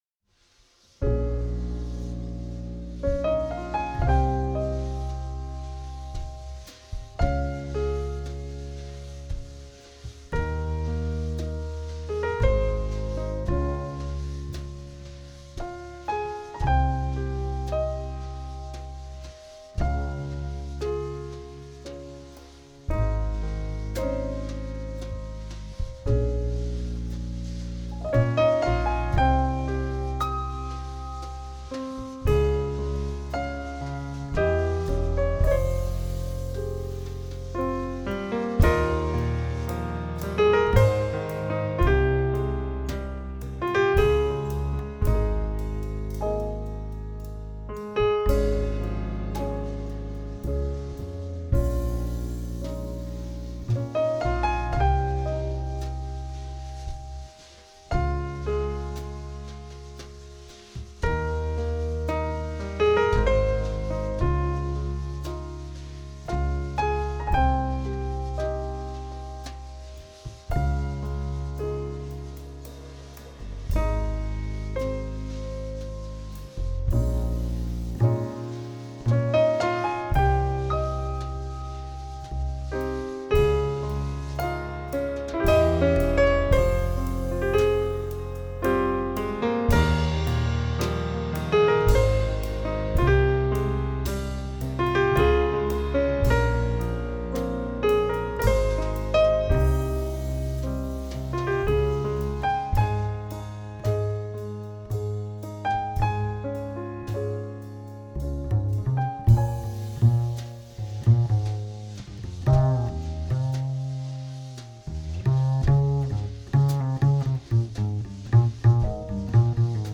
джазового трио
негромким, чутким аккомпанементом
jazz